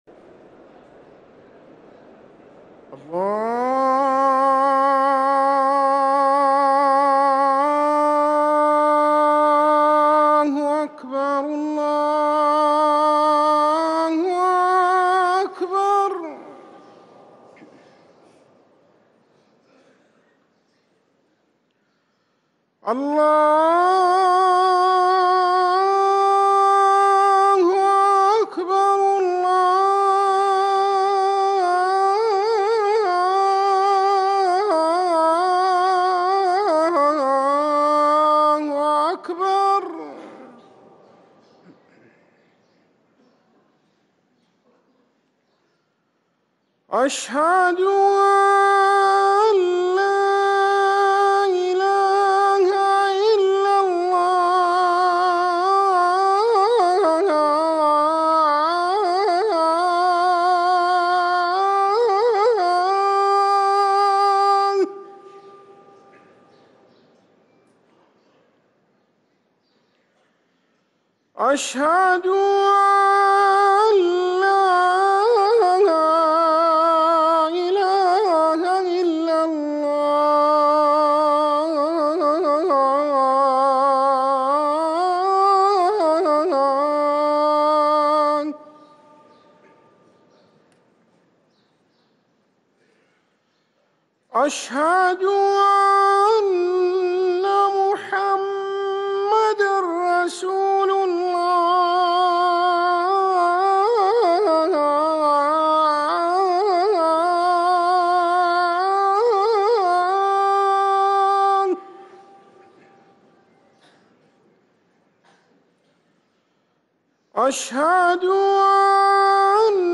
أذان الفجر